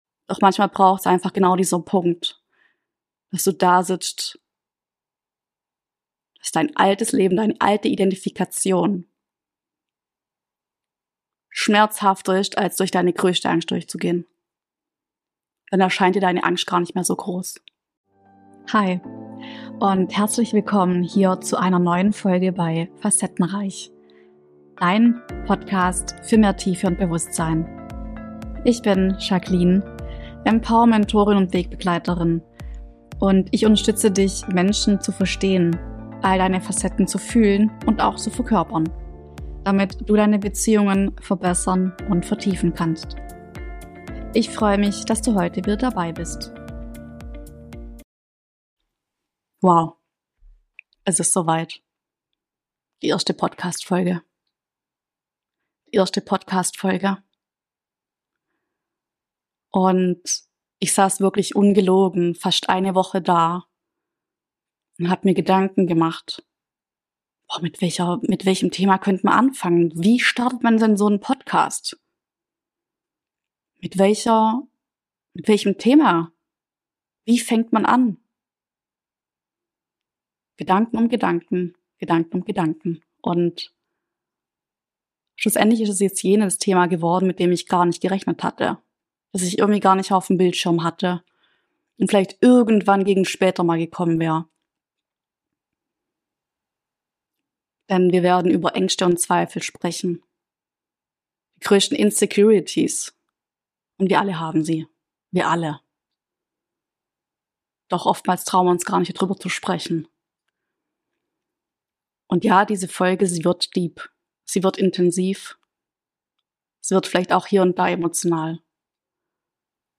Aufgenommen mitten in der Nacht und völliger Dunkelheit, erzähle ich dir von meinen Erfahrungen mit Ängsten und Zweifeln und wie ich gelernt habe trotz meinen Ängsten loszugehen und meine Ausdrucksfähigkeit zu nutzen.